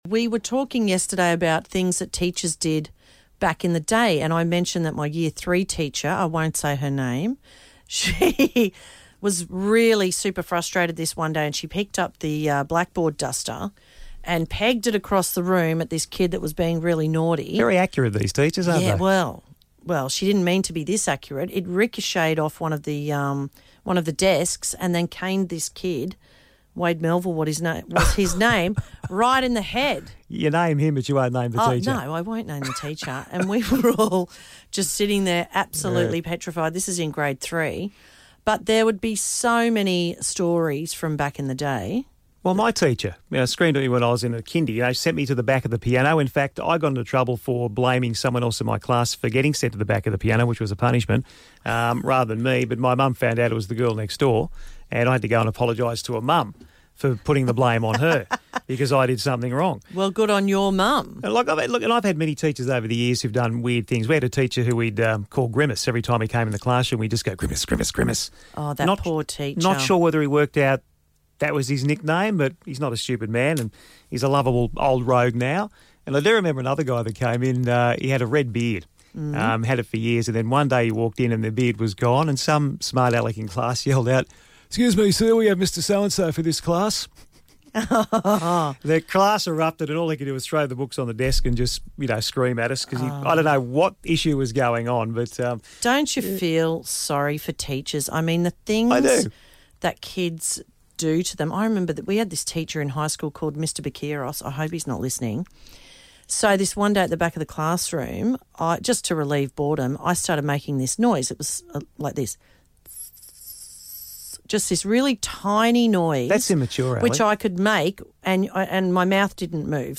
chat to listeners about the crazy mixed up things teachers dished out back in the day.